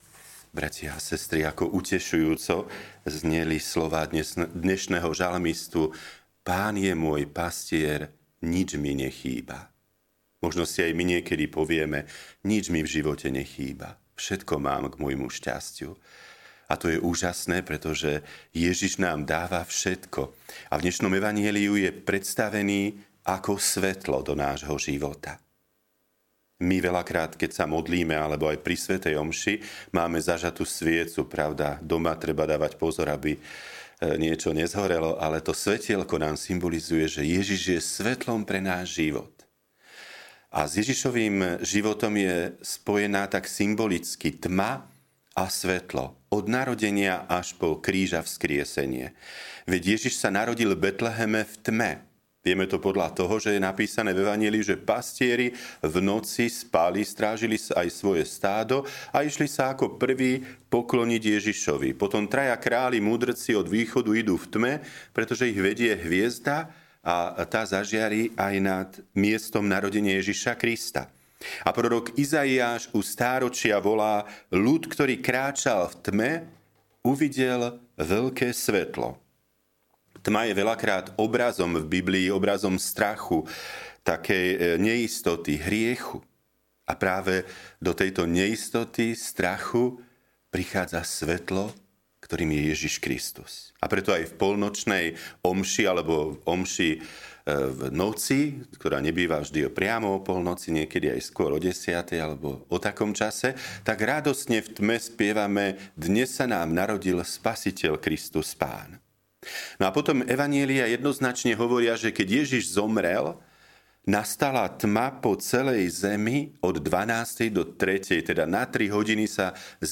Podcast Kázne